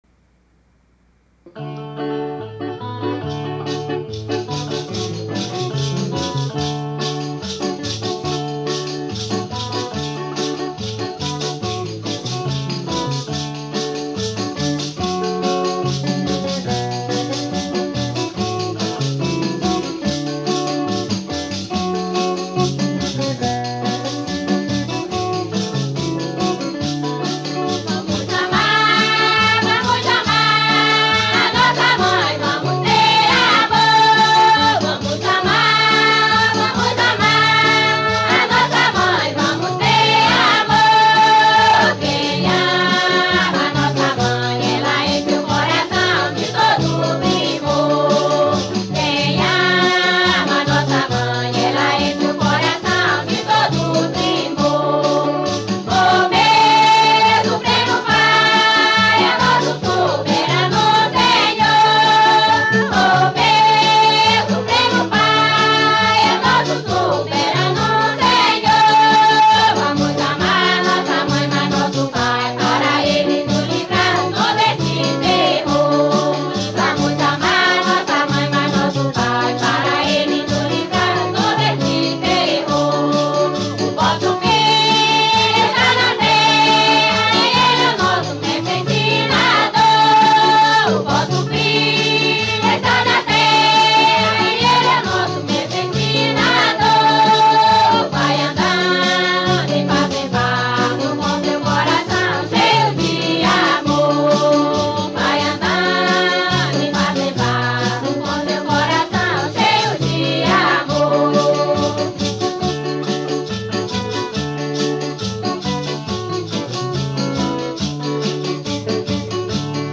marcha